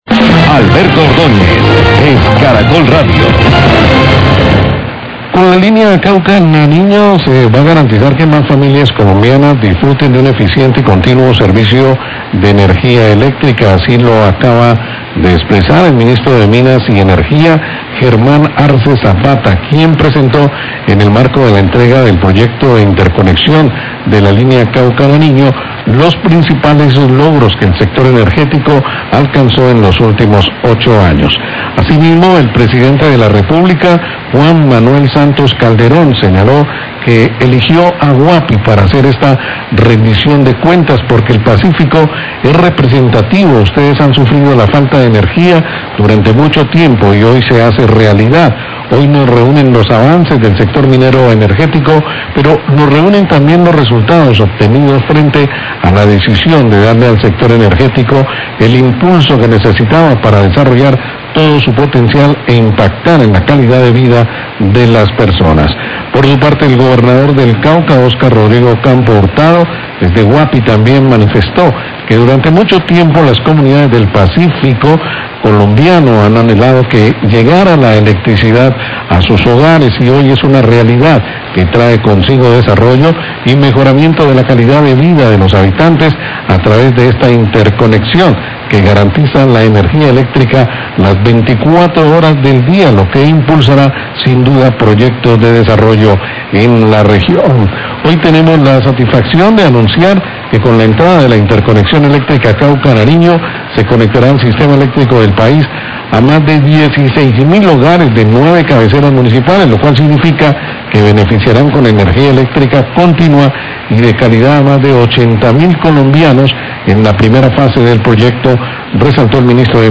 Radio
Ministro de Minas y Energía, Germán Arce, hace un balance de los principales logros del sector energético durante estos 8 años. Declaraciones en el marco de la entrega de la línea Cauca-Nariño de interconexión electrica.